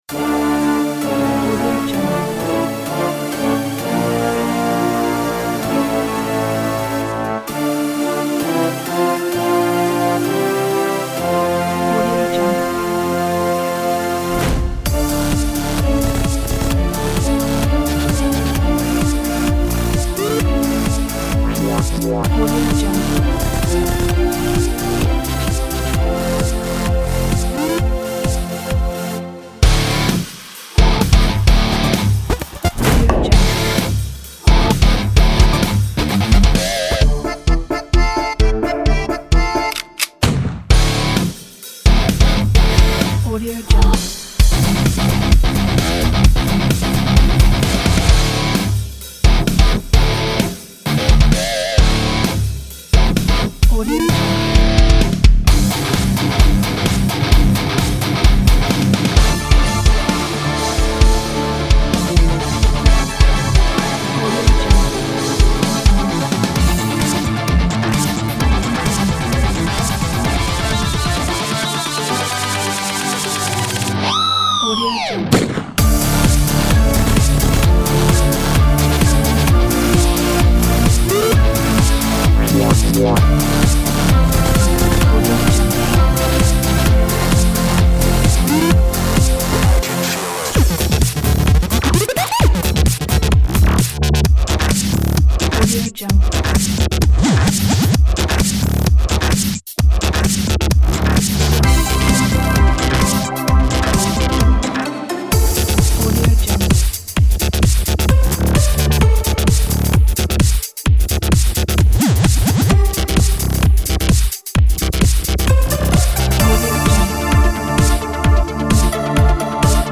Гитары без вокала - слышно, что клавишные, но под вокалом...
И сделано с помошью кастом стилей за час!